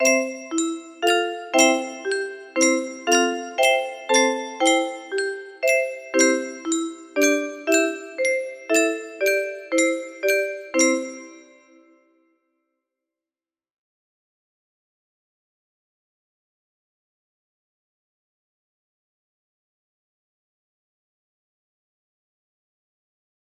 Mari Muliakan Maria music box melody